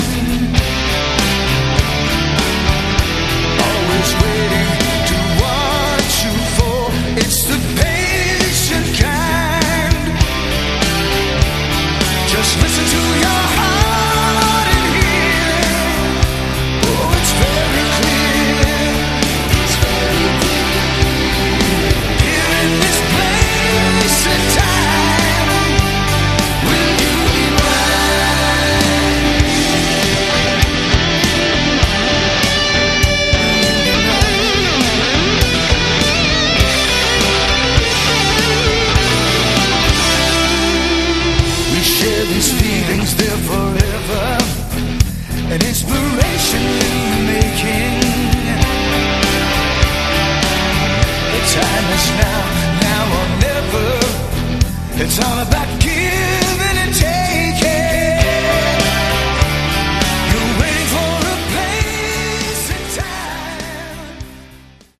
Category: AOR
Guitar, Backing Vocals
Bass
Keyboards
Drums